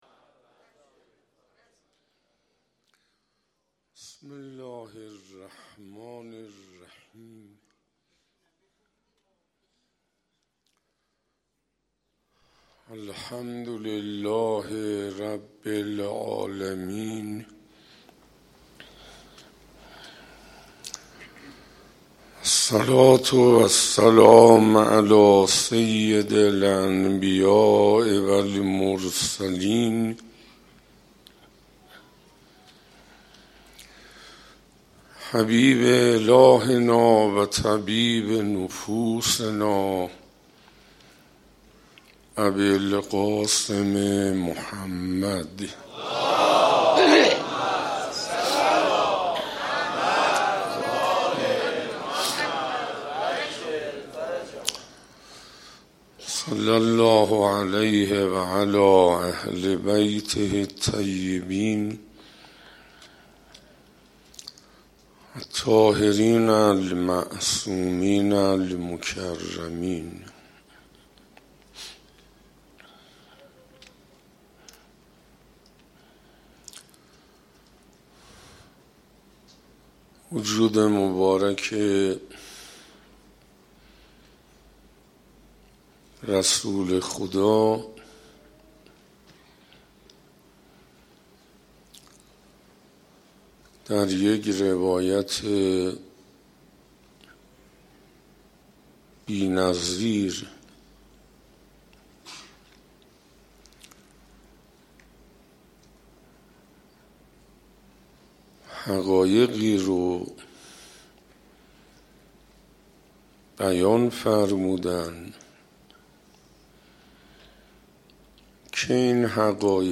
شهدای ایران : حجت‌الاسلام حسین انصاریان استاد حوزه و مفسر قرآن کریم طی سخنرانی در حسینیه هدایت به مناسبت ماه مبارک رمضان، به بیان خصائل و ویژگی‌های معنوی و اخلاقی امیرالمومنین (ع) پرداخت و اظهار داشت: وجود مبارک رسول خدا (ص)، در یک روایت بی‌نظیر حقایقی را بیان فرمودند که این حقایق به صورت اکمل از مشرق وجود امیرالمومنین (ع) طلوع کرد و همچنین از مشرق وجود هر مومنی به اندازه ظرفیتش طلوع خواهد کرد.